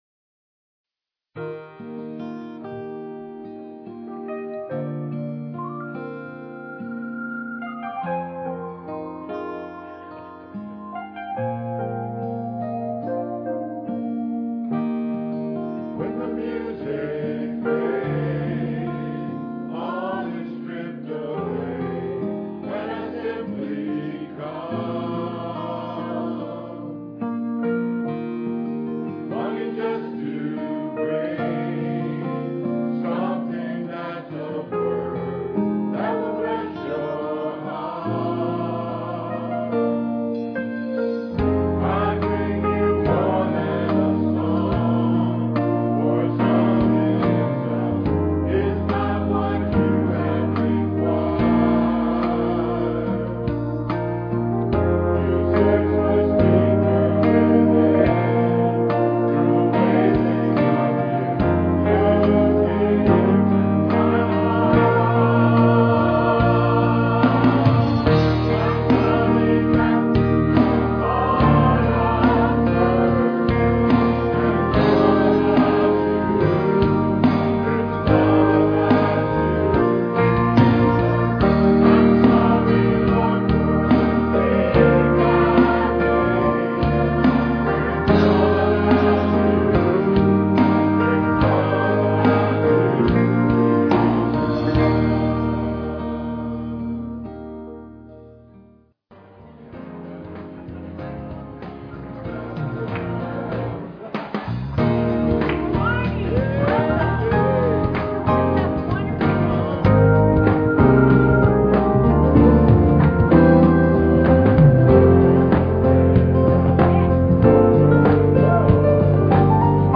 Scripture: Exodus, chapters 3 and 4, excerpts read
Piano and Organ duet